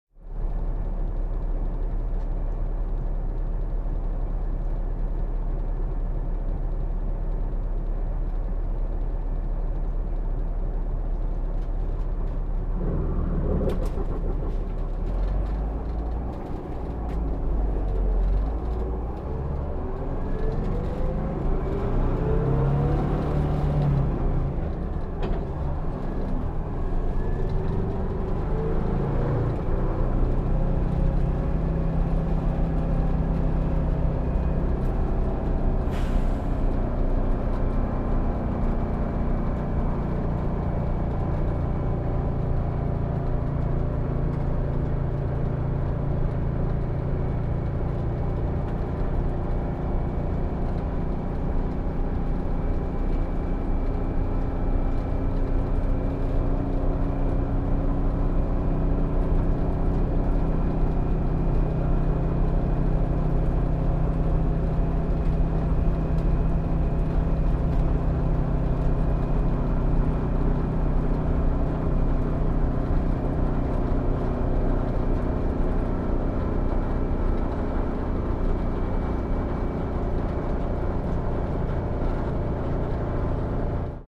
Aja bussilla | Lataa ääniefektit .mp3.
Aja bussilla | äänitehoste .mp3 | Lataa ilmaiseksi.